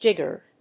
音節jig・gered 発音記号・読み方
/dʒígɚd(米国英語), dʒígəd(英国英語)/